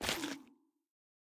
Sculk_step1.ogg.ogg